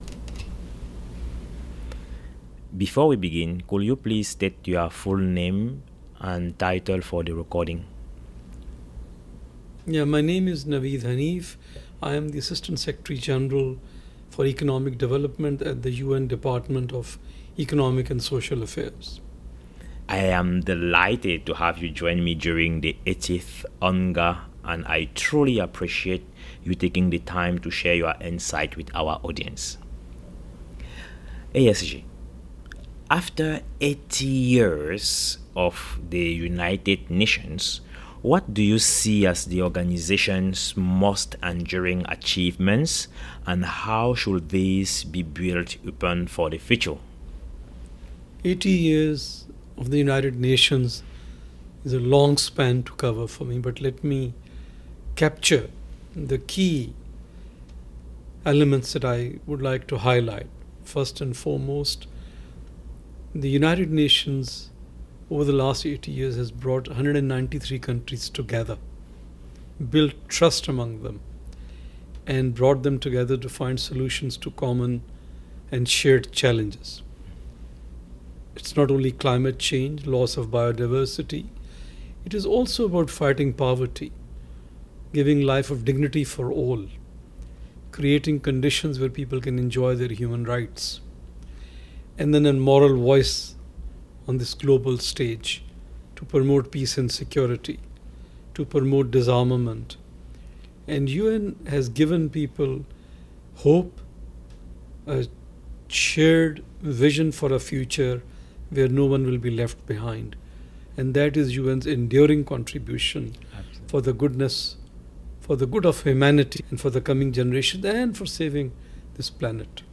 Foresight Africa Podcast